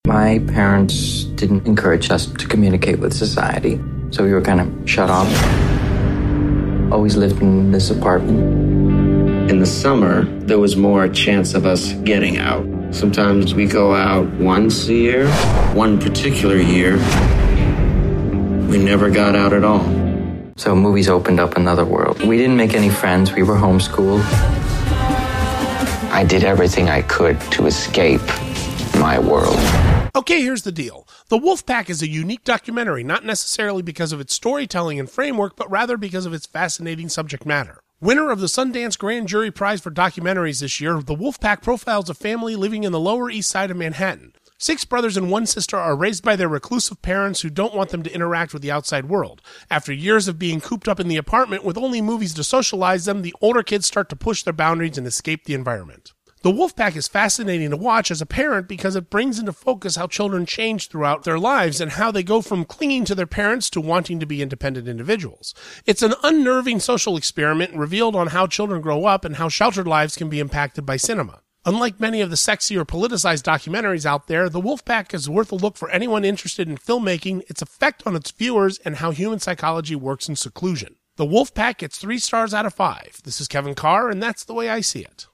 Movie Review
Download this Review